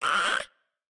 Minecraft Version Minecraft Version snapshot Latest Release | Latest Snapshot snapshot / assets / minecraft / sounds / mob / ghastling / hurt1.ogg Compare With Compare With Latest Release | Latest Snapshot
hurt1.ogg